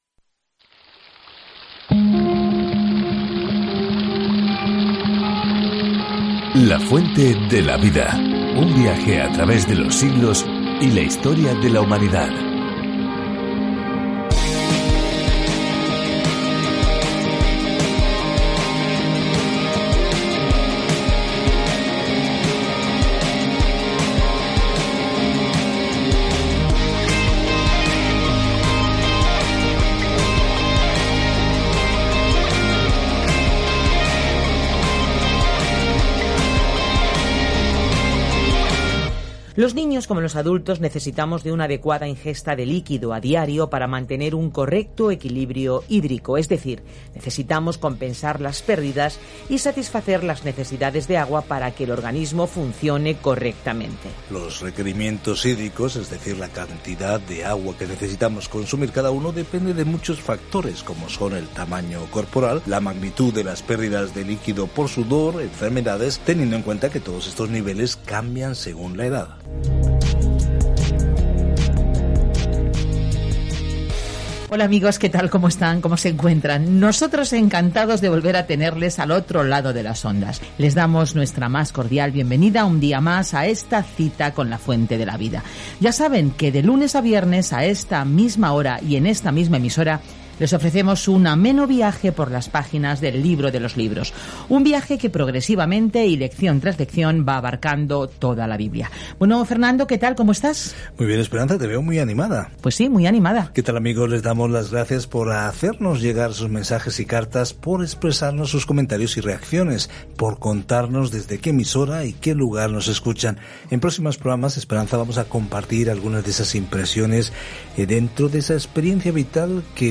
Escritura FILIPENSES 2:8-11 Día 7 Iniciar plan Día 9 Acerca de este Plan Esta nota de “gracias” a los filipenses les brinda una perspectiva gozosa de los tiempos difíciles que atraviesan y los alienta a superarlos juntos con humildad. Viaja diariamente a través de Filipenses mientras escuchas el estudio en audio y lees versículos seleccionados de la palabra de Dios.